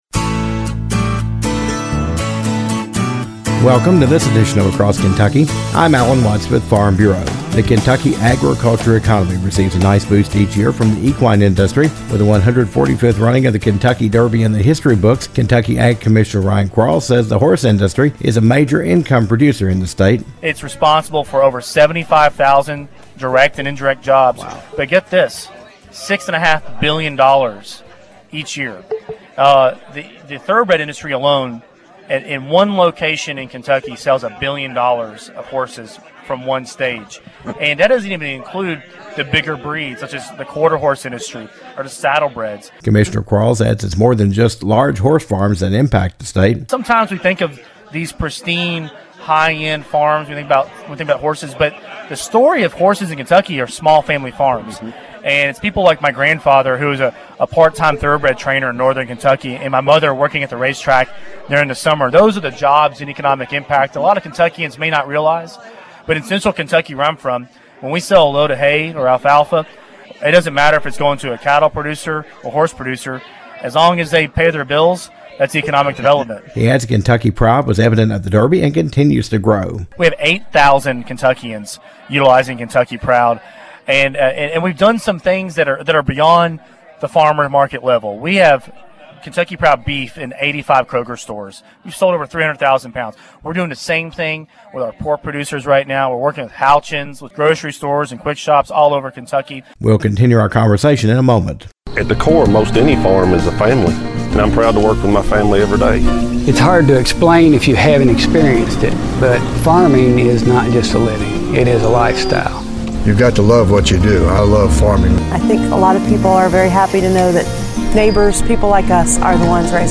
Kentucky Agriculture Commissioner Ryan Quarles says the equine industry has a large impact on the state of Kentucky.